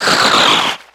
Cri de Stalgamin dans Pokémon X et Y.